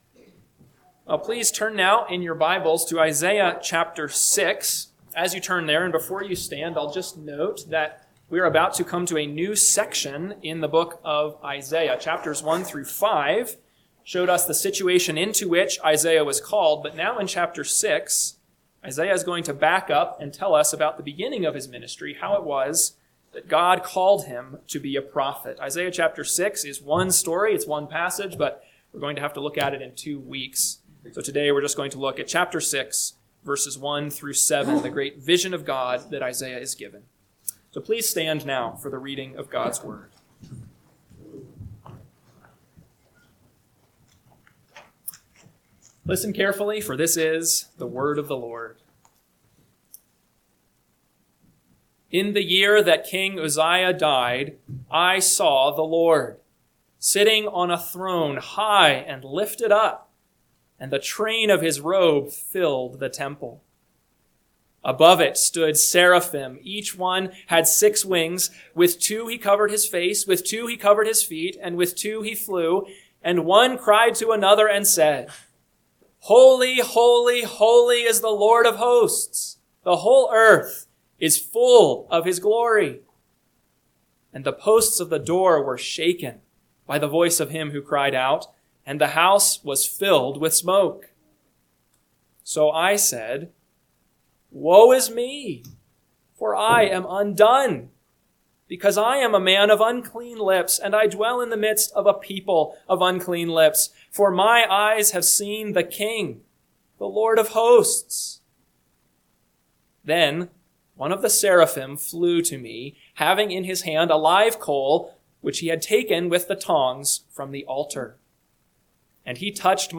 AM Sermon – 12/7/2025 – Isaiah 6:1-7 – Northwoods Sermons